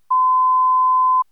ettusentjugofem.wav